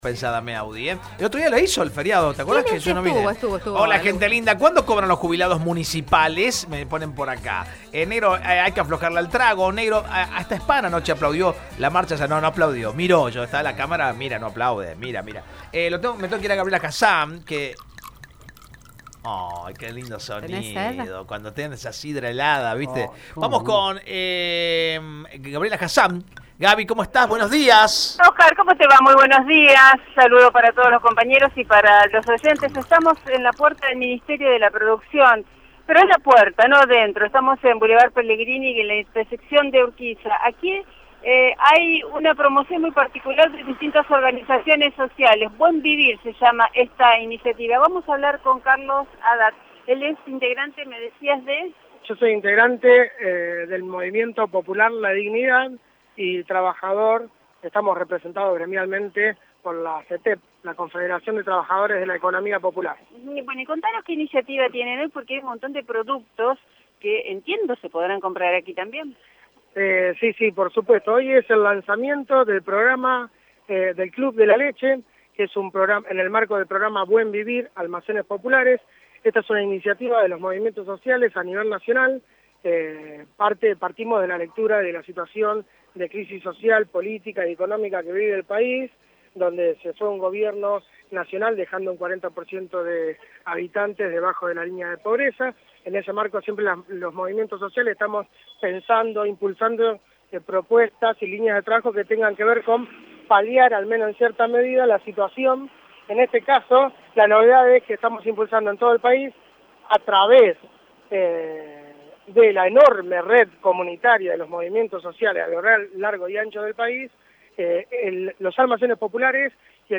En la explanada del Ministerio de la Producción, el Movimiento Popular La Dignidad, presentó el «Club de la Leche» en el marco del programa «El Buen Vivir», que se instala en medio de la crisis económica nacional.